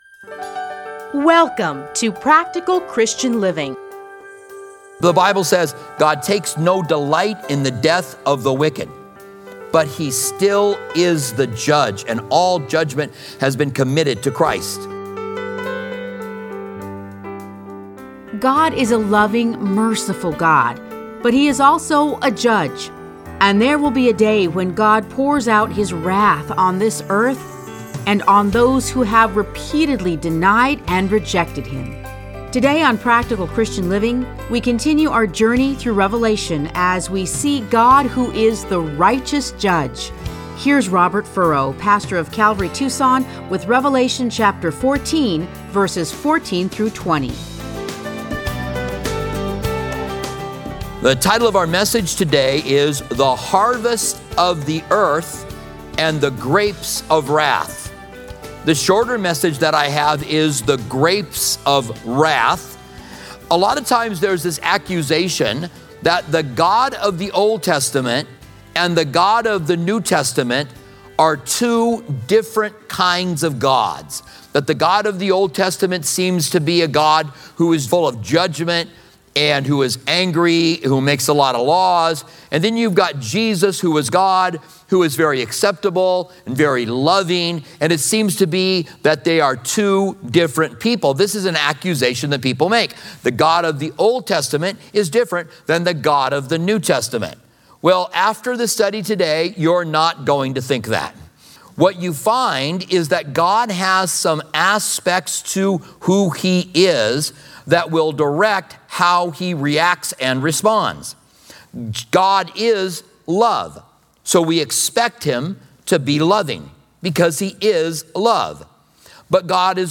Listen to a teaching from Revelation 14:14-20.